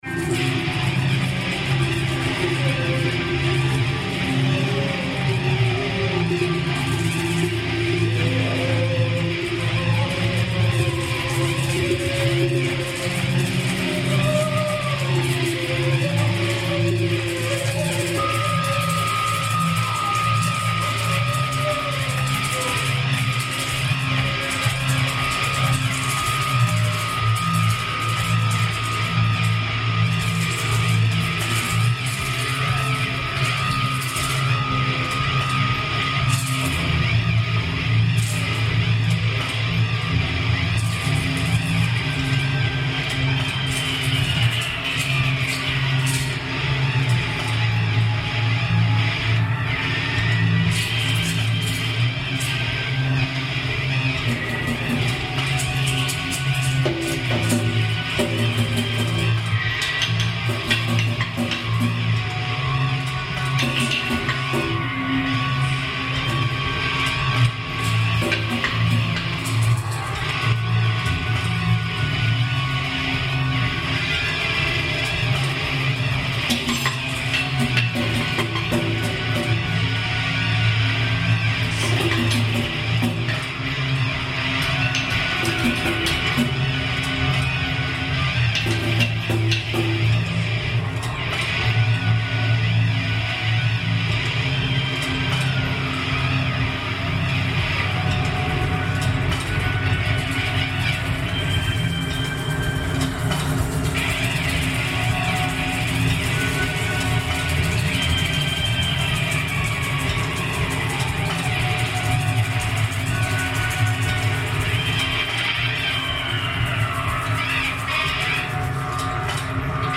Brief clip from our performance accompanying butoh dancers